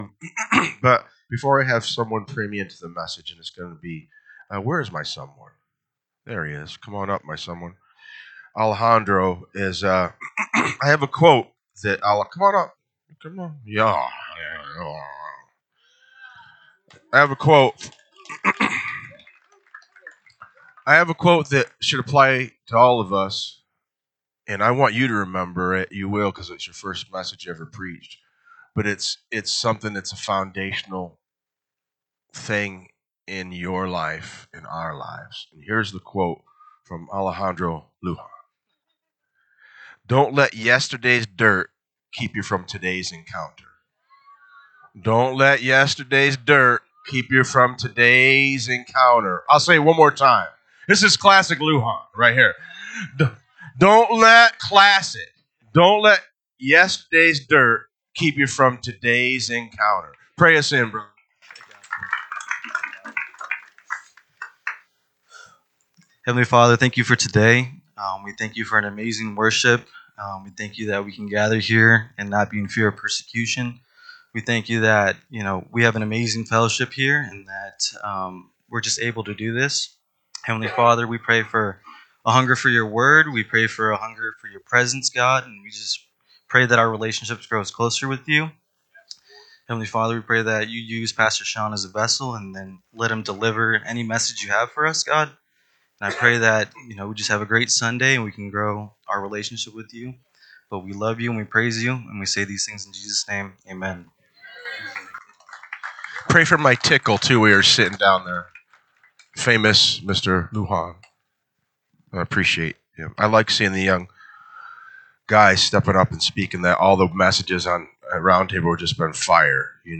NSCF Sermons Online Acts of the Apostles Ep. 13 Oct 20 2025 | 00:58:04 1x 00:00 / 00:58:04 Subscribe Share RSS Feed Share Link Embed